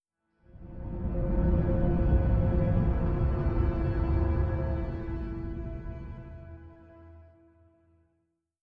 Playstation 12 Startup.wav